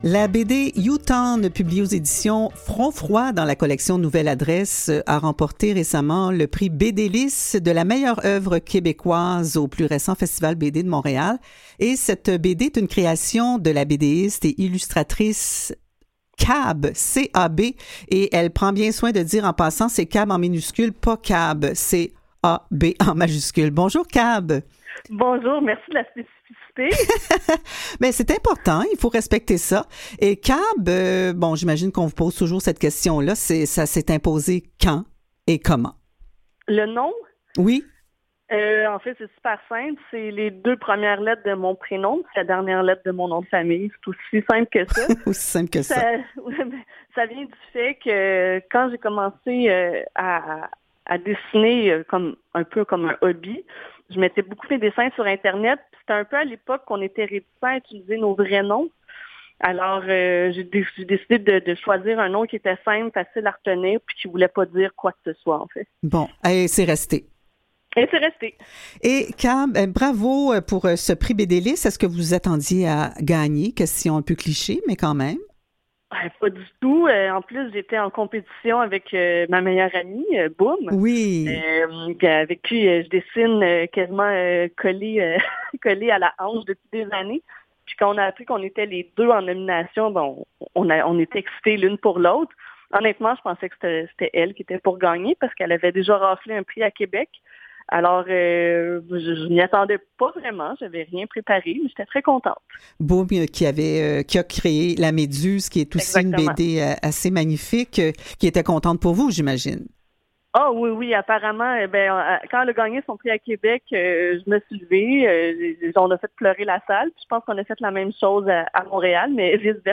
aq-entrevue-cab.mp3